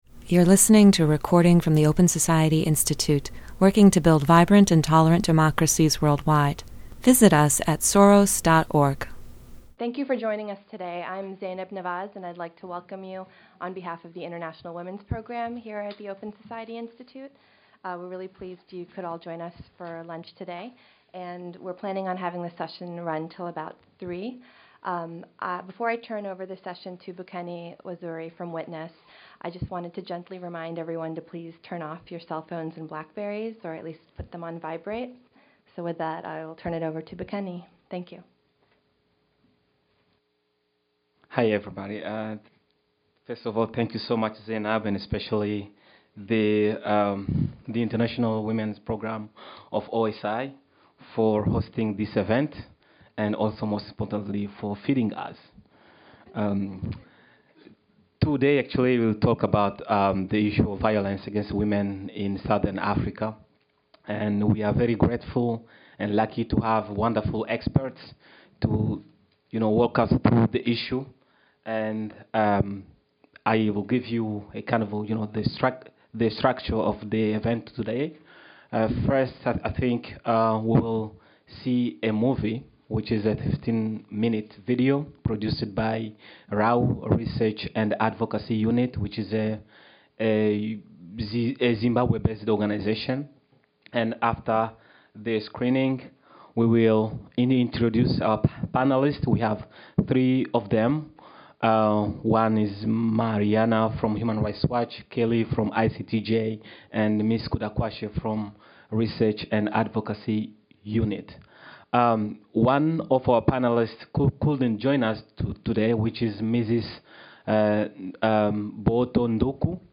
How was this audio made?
Cosponsored by the Open Society Institute and WITNESS, this discussion examines the role of the Southern African Development Community in ending violence against women in the region.